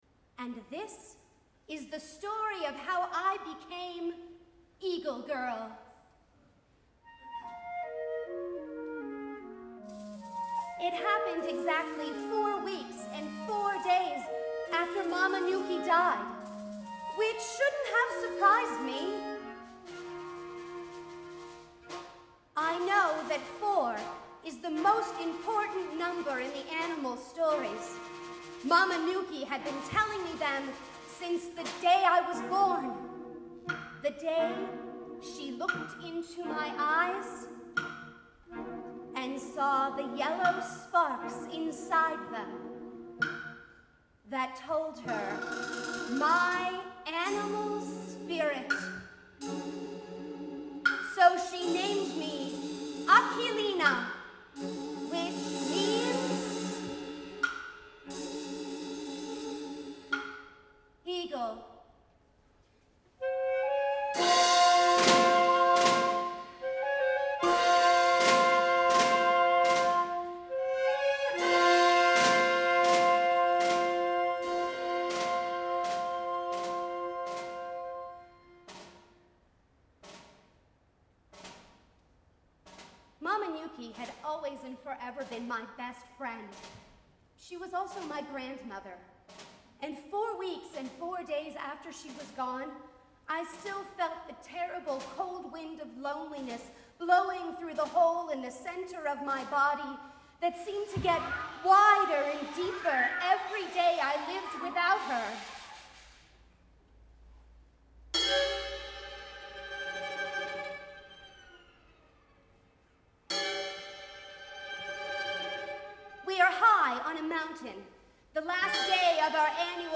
for Narrator/ Singer and 8 Players